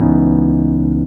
EP CHORD-R.wav